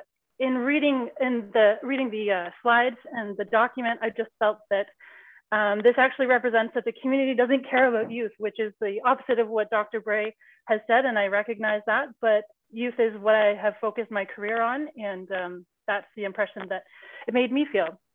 There were multiple public comments against the master plan, specifically against the section that discusses the possibility of altering the skate park that exists there currently.